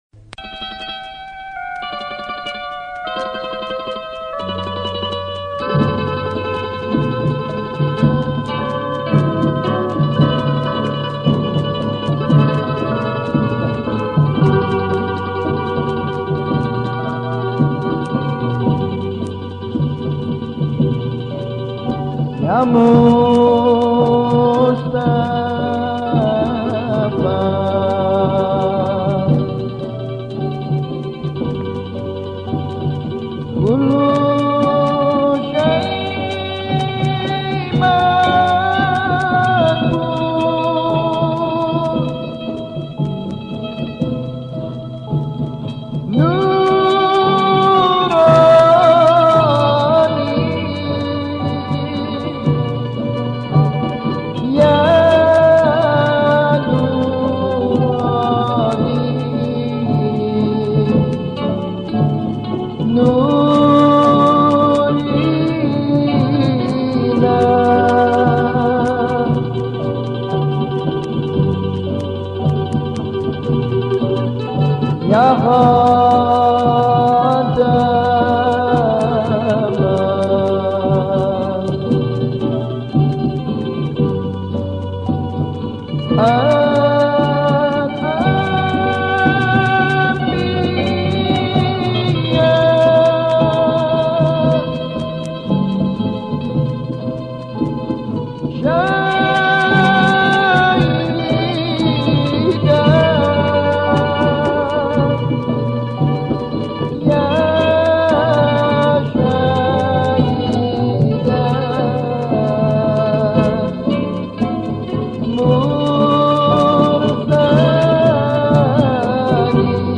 Cape Town Qasidah